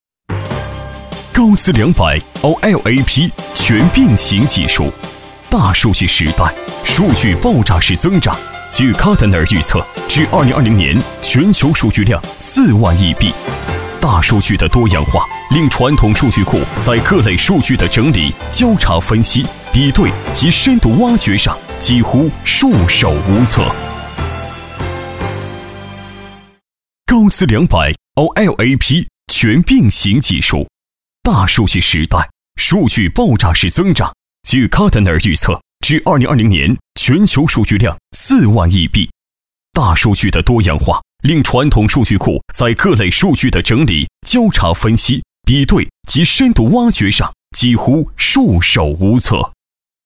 大气震撼 企业专题
青年男音，擅长大气豪迈专题汇报，宣传片，活力mg动画、科技感等题材配音。